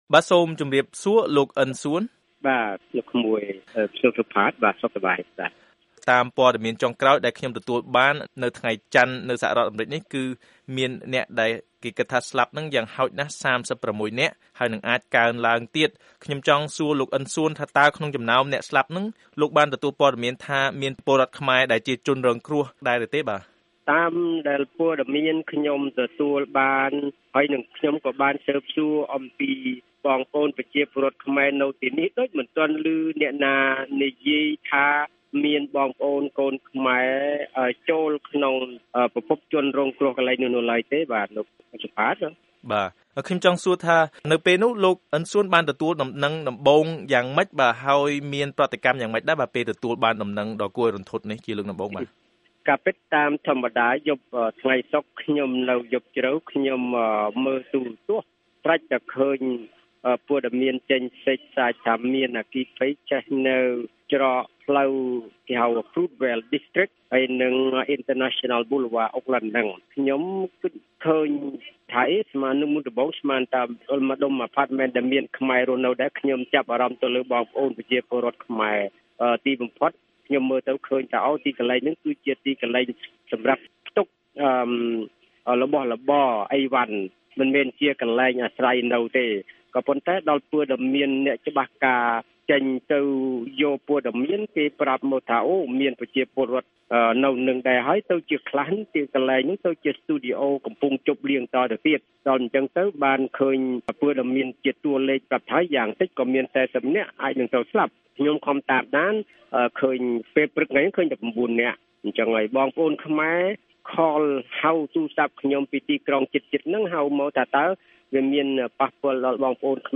បទសម្ភាសន៍ VOA៖ សហគមន៍ខ្មែរនៅក្រុង Oakland រន្ធត់និងរៀនមេរៀនពីអគ្គិភ័យដ៏មហន្តរាយ